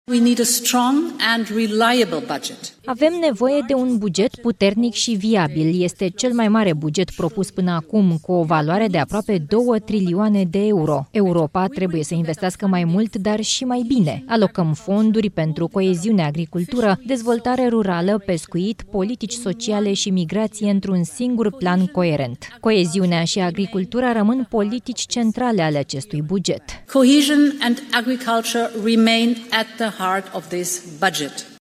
Dezbateri la Parlamentul European. Tema: viitorul buget multianual al UE pentru perioada 2028-2034, care ar putea fi cel mai mare din istoria Uniunii.
Președinta Comisiei Europene, Ursula von der Leyen: „Este cel mai mare buget propus până acum”
13nov-12-Ursula-noul-buget-al-UE-TRADUS-.mp3